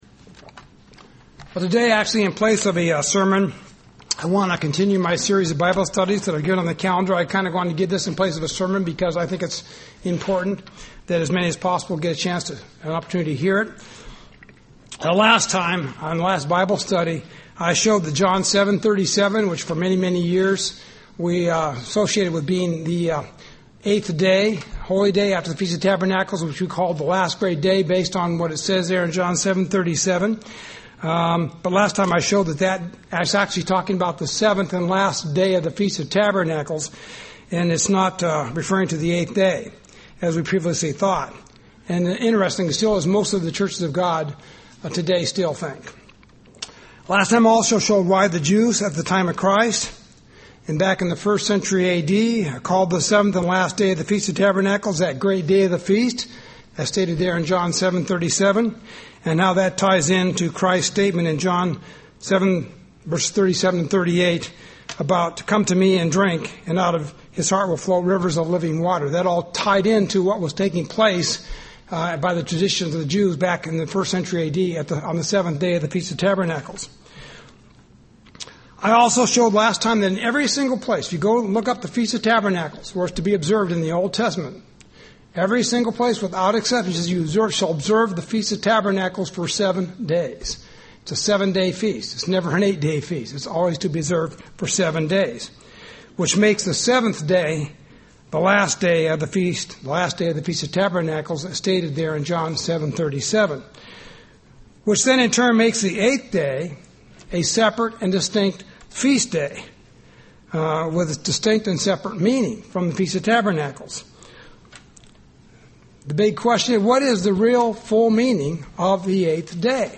This is a study of the Hebrew calendar, the calendar used by God's Church to calculate Holy time and festivals - Part 8.